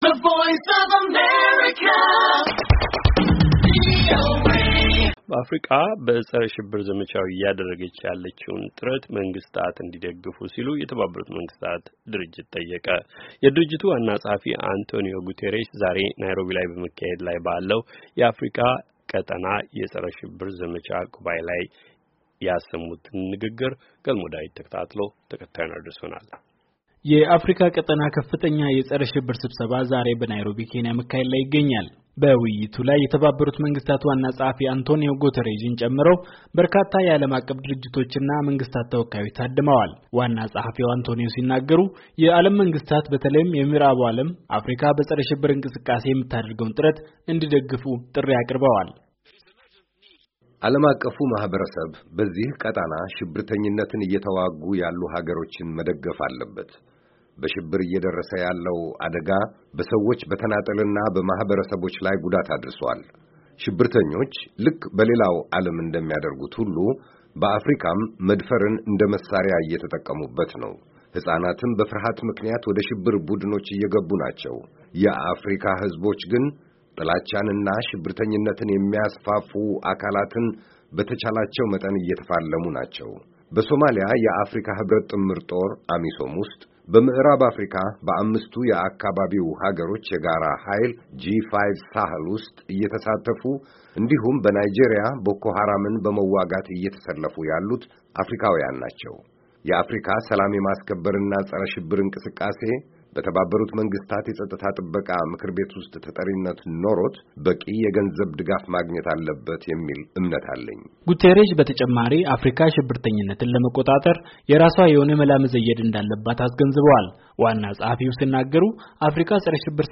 የድርጅቱ ዋና ጸኃፊ አንቶንዮ ጉቴሬዥ ዛሬ በናይሮቢ በመካሄድ ላይ ባለዉ የአፍሪካ ቀጠና ከፍተኛ የፀረ ሽብር ስብሰባ ላይ ያደረጉትን ንግግር ተከታትለናል፡፡